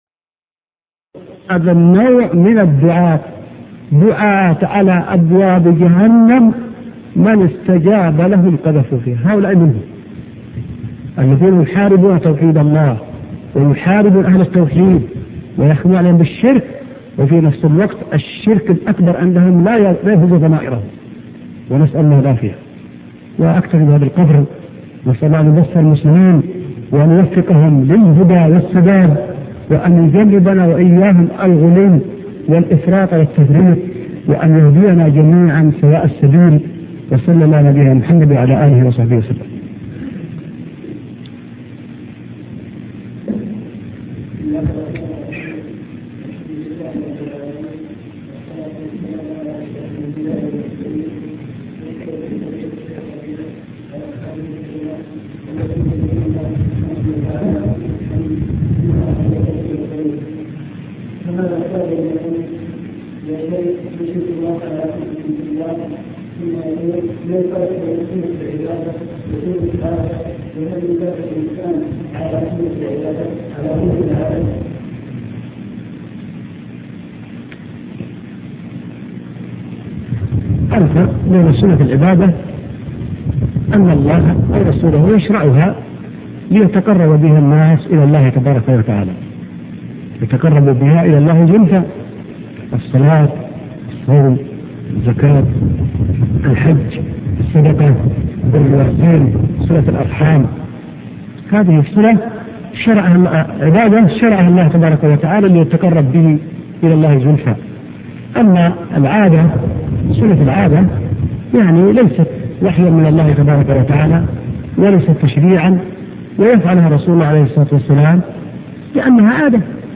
محاضرة السنة بين الغلو و التقصير 3 الشيخ ربيع بن هادي المدخلي